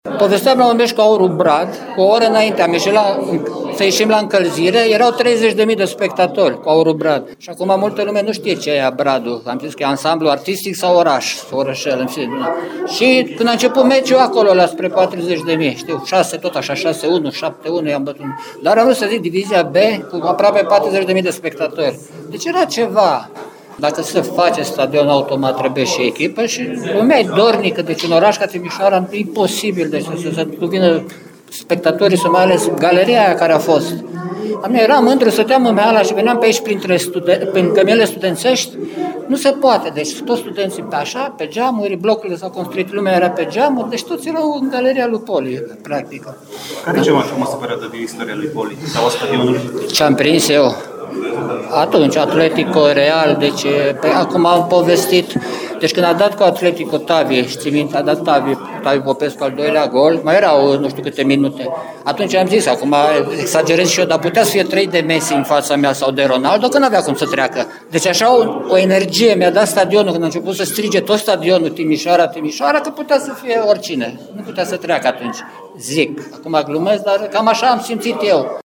Mai mulți fotbaliști legendari de la Poli au fost prezenți la deschiderea expoziției.